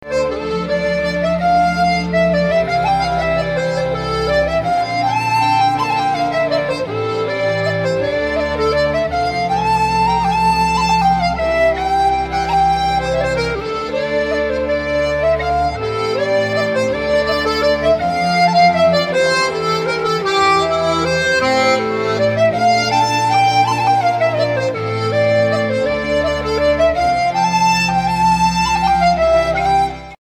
Fiddle
Accordion